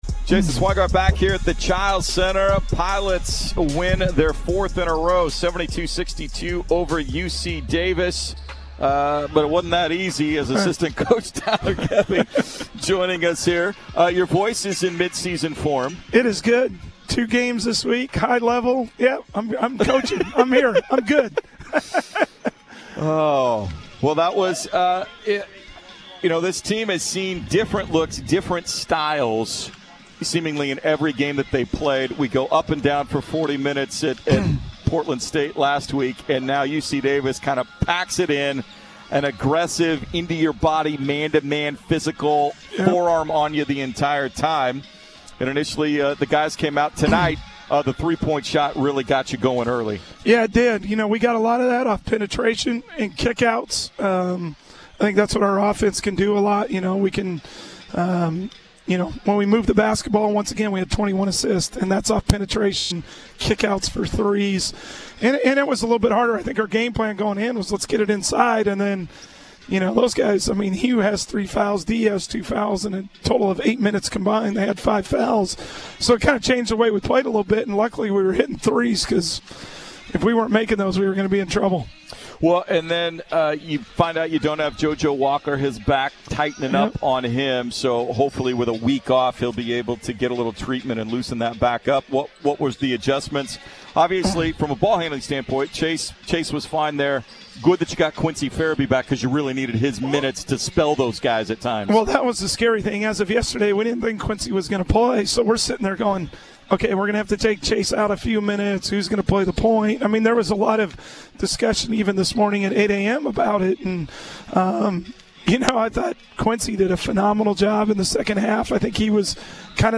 Men's Hoops Post-Game Interviews vs. UC Davis